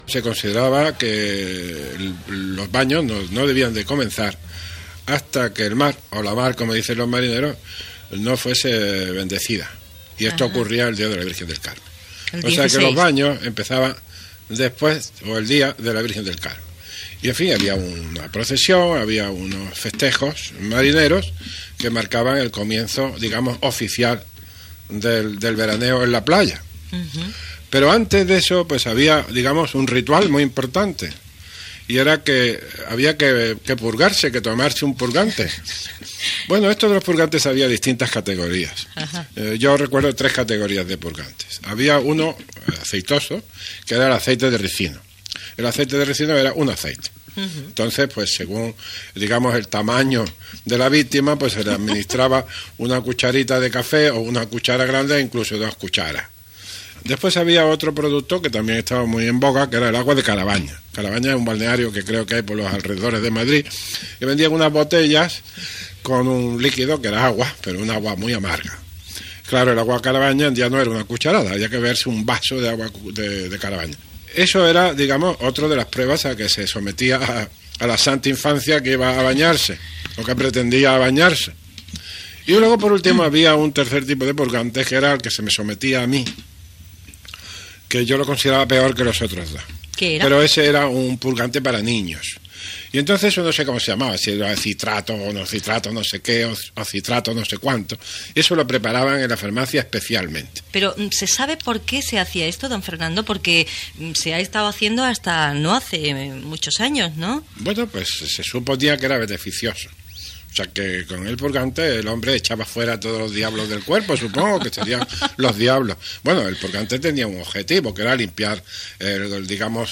Entrevista
Entreteniment